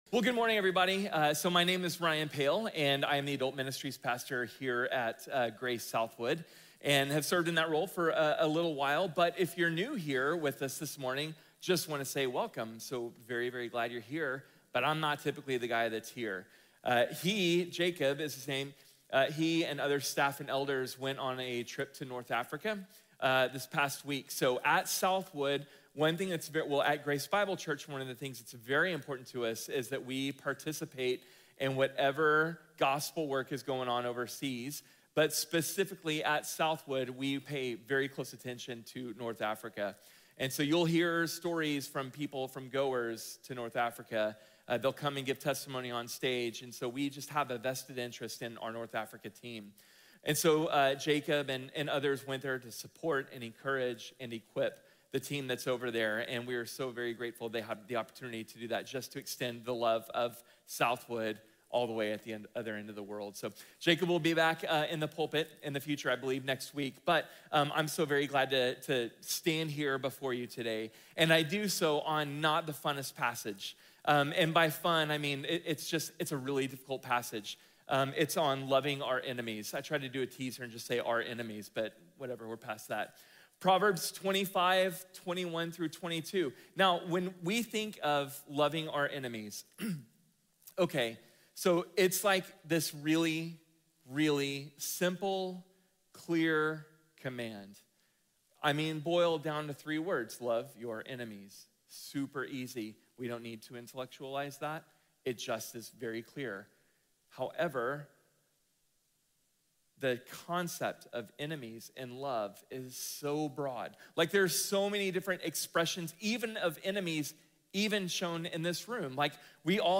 Loving our Enemies | Sermon | Grace Bible Church